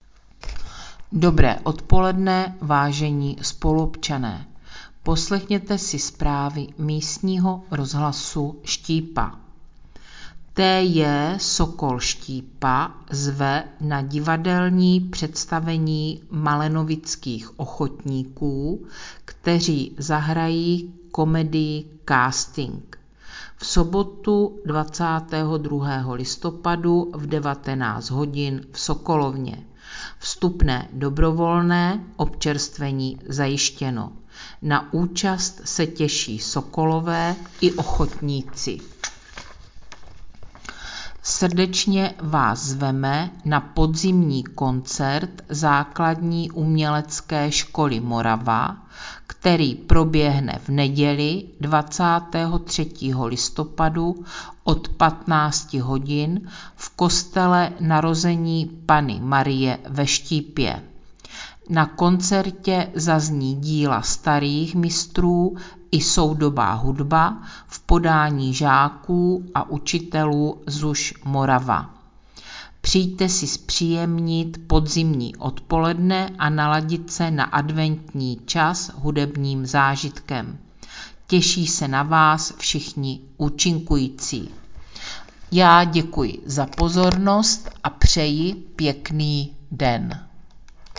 Hlášení místního rozhlasu